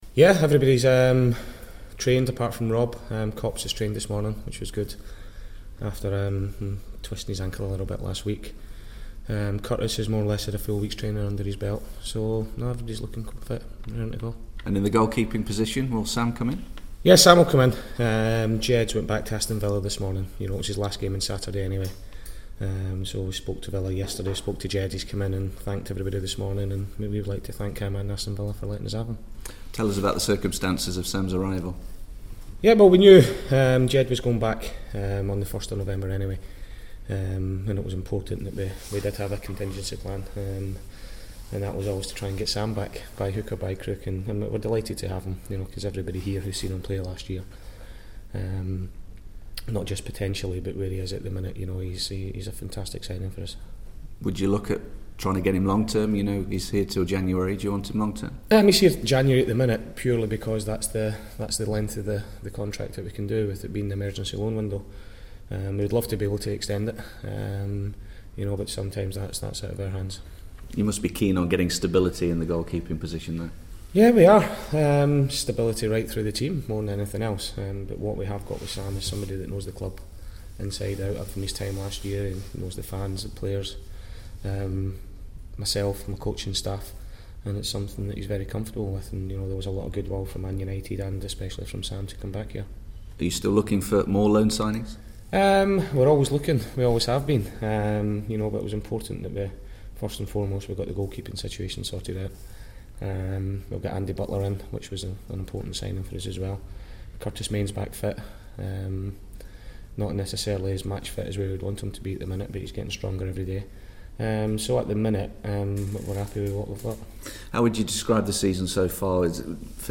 INTERVIEW: Doncaster Rovers manager Paul Dickov ahead of their Yorkshire derby against Bradford City